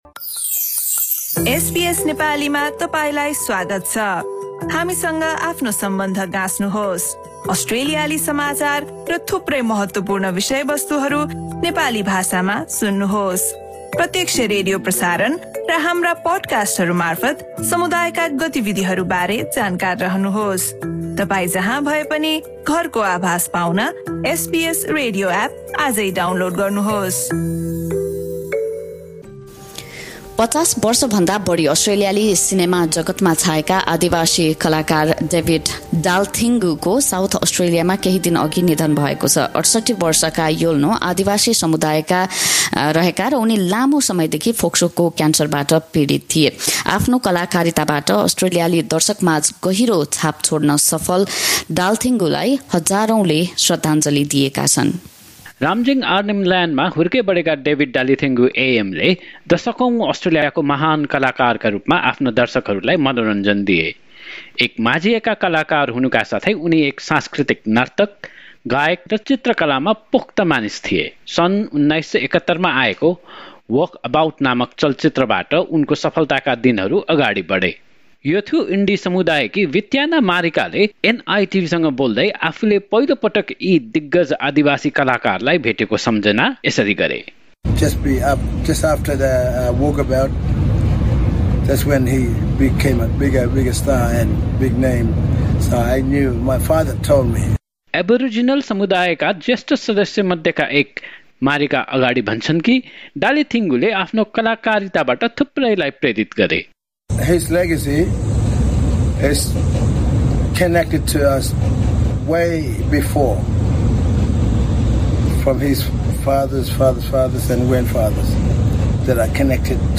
५० वर्षभन्दा बढी अस्ट्रेलियाली सिनेमा जगतमा छाएका आदिवासीय कलाकार डेभिड डाल्थिङ्गुको साउथ अस्ट्रेलियामा केही दिन अघि निधन भएको छ। उनैको सम्झनामा एक रिपोर्ट।